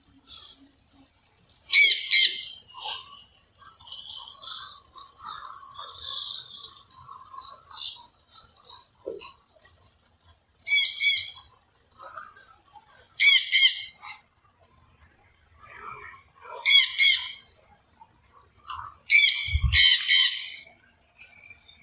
Aratinga Jandaya (Aratinga jandaya)
Nombre en inglés: Jandaya Parakeet
Fase de la vida: Adulto
Localidad o área protegida: Maceio
Condición: Silvestre
Certeza: Vocalización Grabada